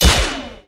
tf2_crit.wav